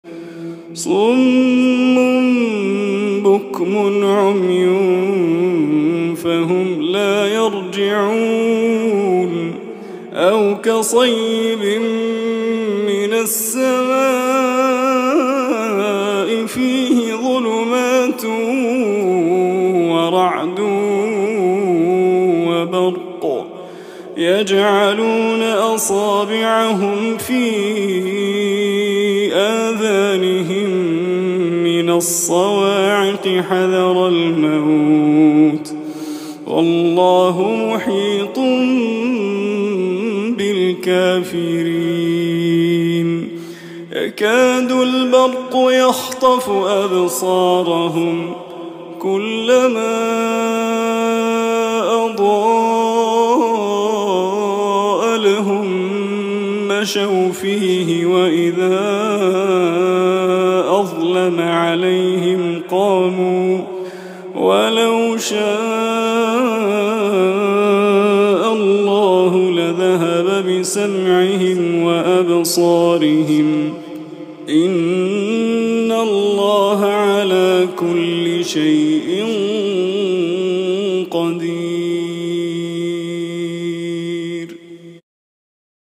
تلاوة خاشعة هادئة من صلاة التراويح لعام 1444هـ